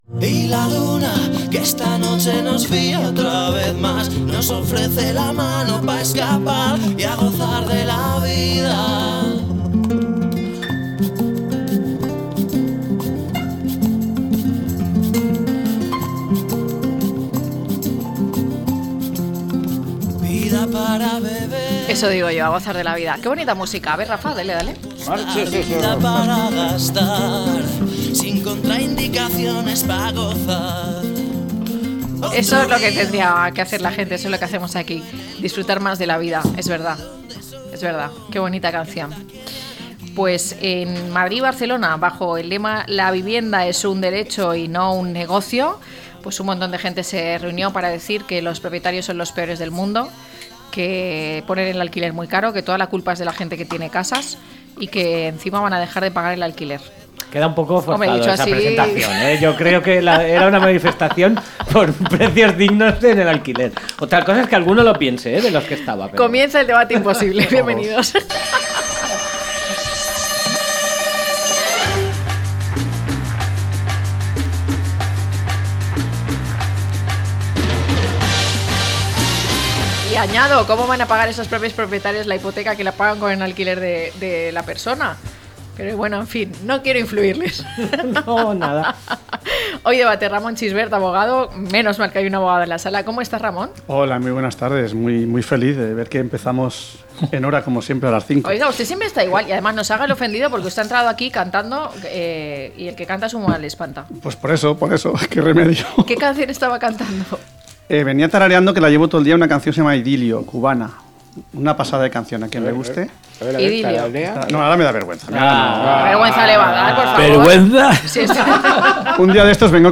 1016-LTCM-DEBATE.mp3